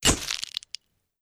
Index of /sound_effects_and_sound_reinforcement/The_Passion_of_Dracula/sounds